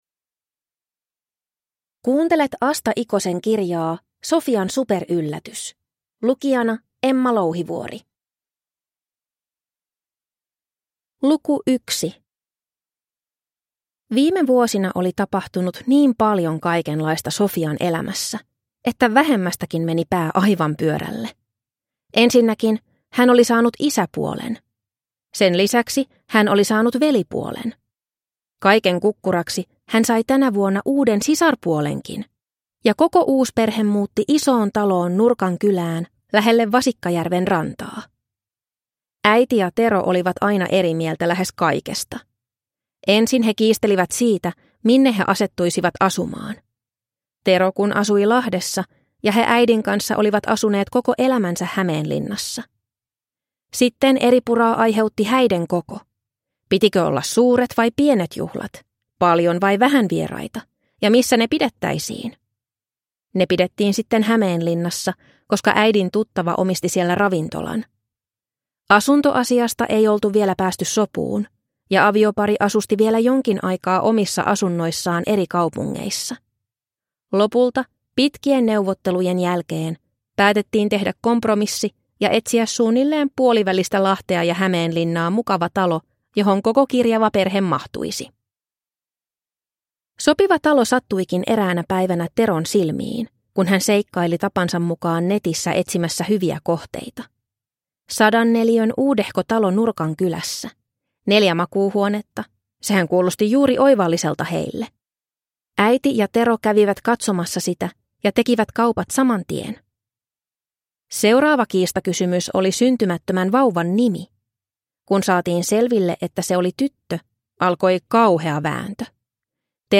Sofian superyllätys – Ljudbok